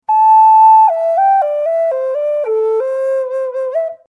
A Minor Concert Flute
It is a relative C major, which is a great contemporary scale.
The nest enhances the performance by giving you a really alive, present voice that can handle big breath extremes.
They have a total of 15 notes, covering an octave plus a minor third.